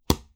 Close Combat Attack Sound 10.wav